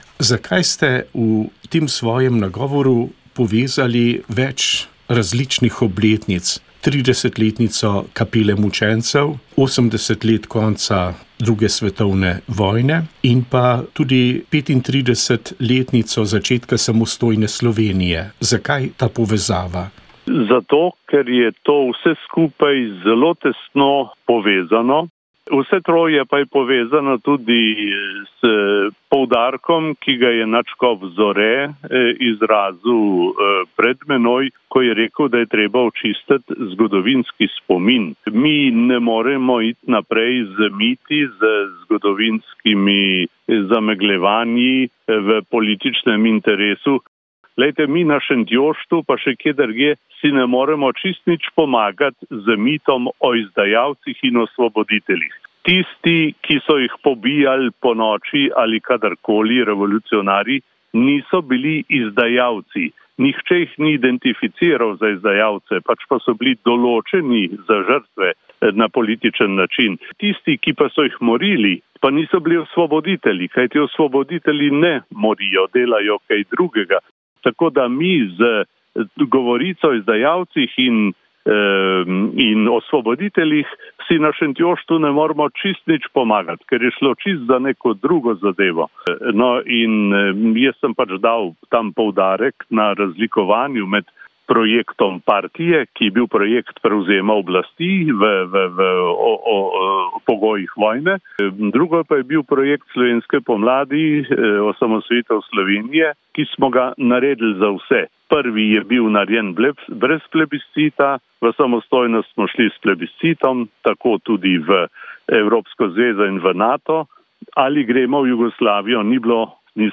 Razpravo državnih svetnikov je sklenil predsednik Alojz Kovšca, ki je spregovoril o očitkih zagovornikov zakona, ki ga je pripravilo ministrstvo za izobraževanje pod vodstvom Jerneja Pikala. Precej oster in tudi oseben nagovor objavljamo v celoti.